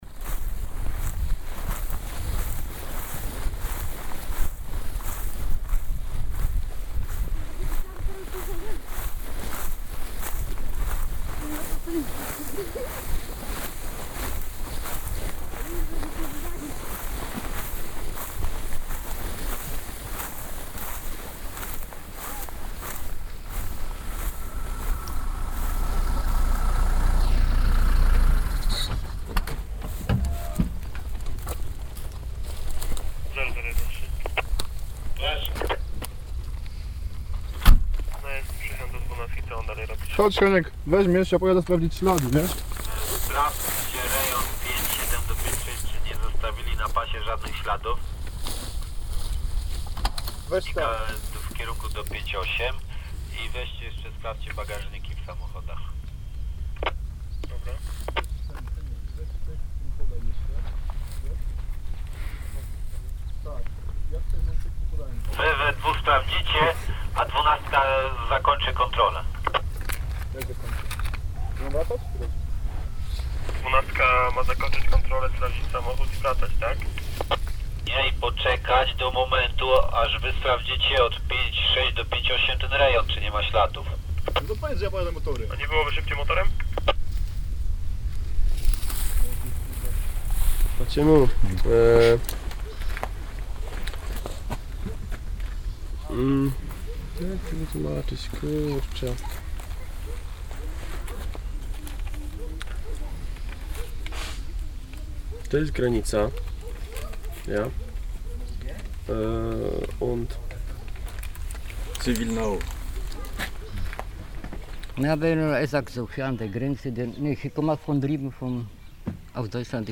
Composed from field recordings and interviews, "border sounds" forces the larger question of how might soundscapes resist a "Beautiful World" tendency to inform and interrogate political process.
Control by border patrol and talk with residents, Pargowo, Poland